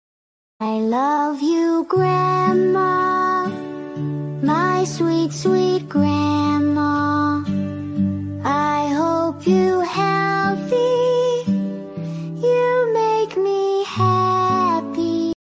This little sweetheart is singing sound effects free download
This little sweetheart is singing “I love you, Grandma” with the softest meows and the biggest heart—get ready to cry happy tears!